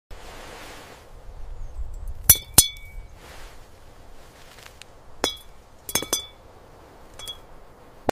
ASMR glass garden vegetables, eggplant